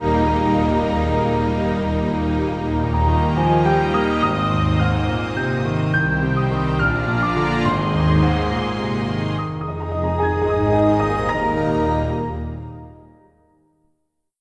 Windows NT 5.1 Startup.wav